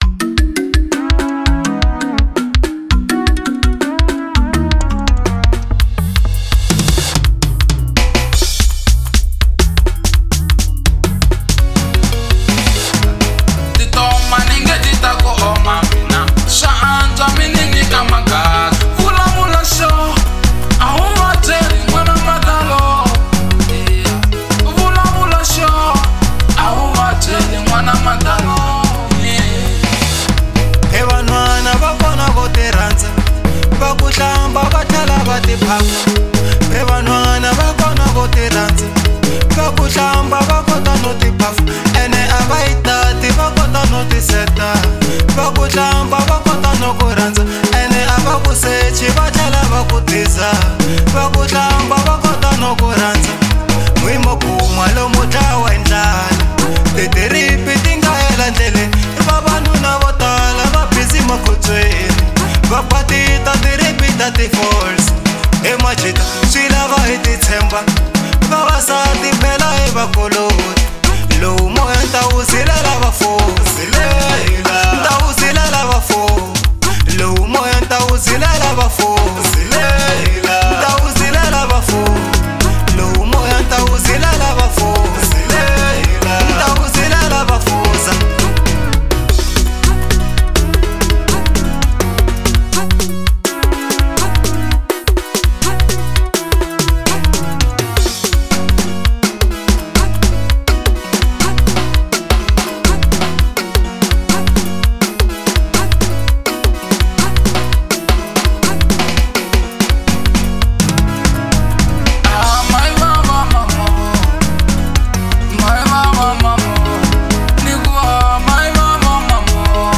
03:08 Genre : Xitsonga Size